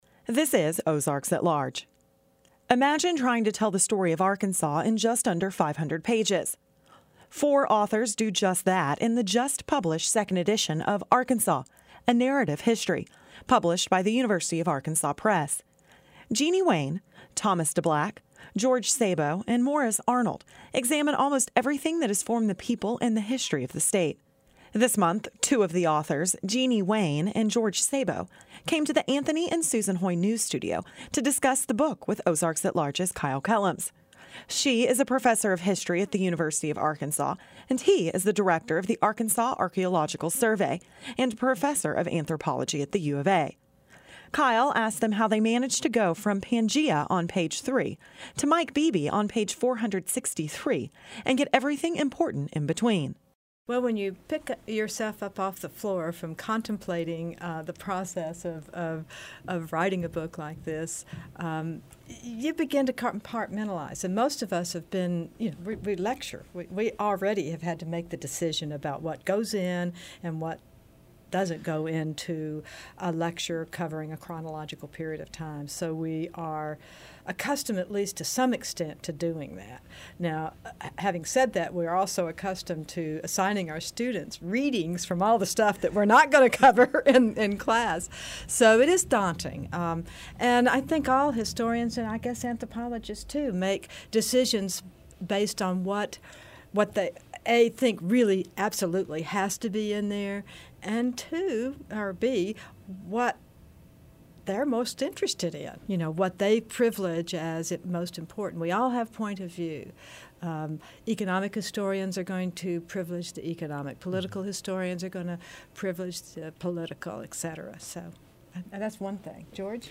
We speak with two of the book's four authors about how they condensed the state's history,